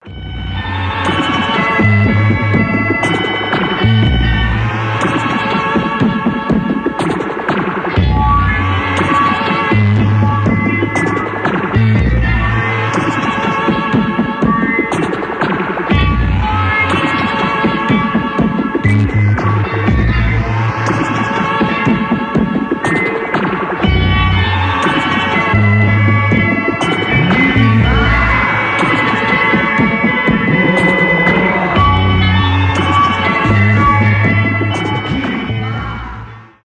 8 бит , experimental
психоделика , электронные